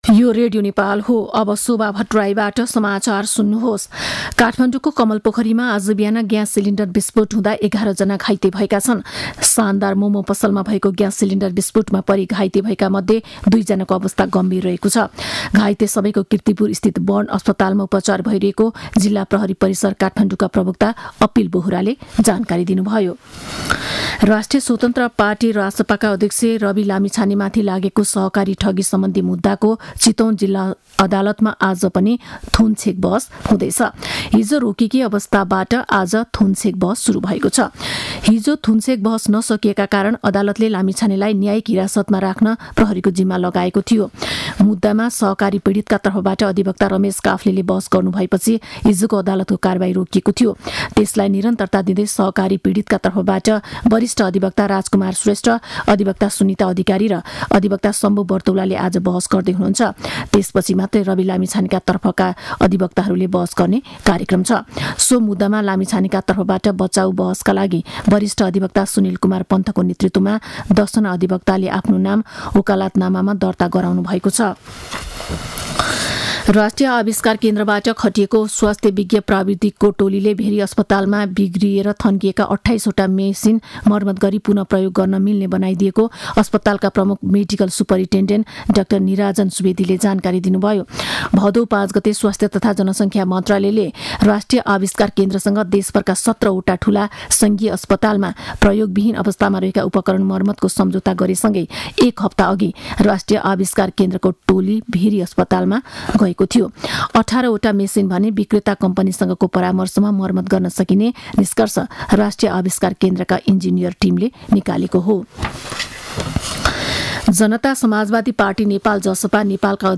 मध्यान्ह १२ बजेको नेपाली समाचार : २५ माघ , २०८१